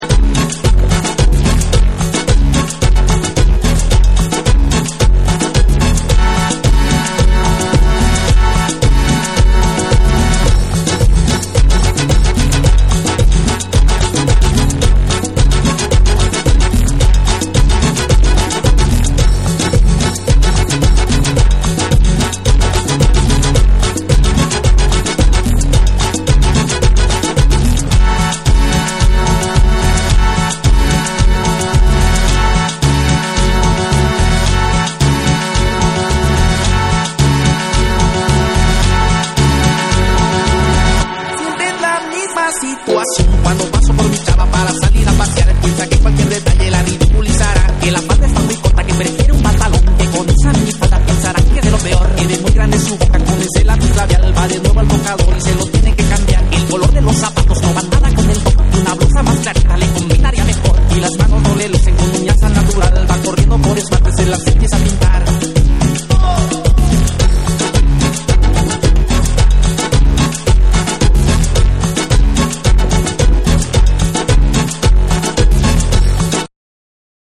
BREAKBEATS / ORGANIC GROOVE / NEW RELEASE